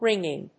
音節wríng・ing 発音記号・読み方
/ˈrɪŋɪŋ(米国英語)/